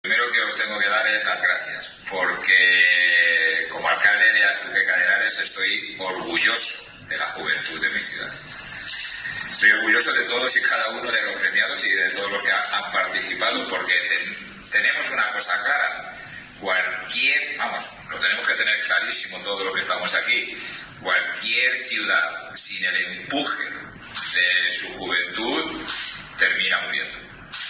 Declaraciones del alcalde José Luis Blanco 1
El acto, celebrado en el Centro de Empresas Azuqueca Emprende, ha contado asimismo con la asistencia de la primera y segunda teniente de alcalde, María José Pérez y Susana Santiago, respectivamente, el concejal Europa, Miguel Óscar Aparicio y el edil de Azuqueca Futuro, Enrique Pérez de la Cruz, y profesorado del IES Profesor Domínguez Ortiz.